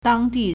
当地 (當地) dāngdì
dang1di4.mp3